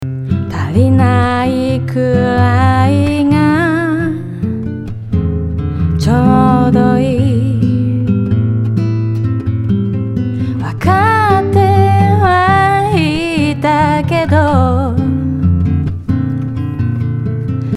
同じリバーブをかけた前回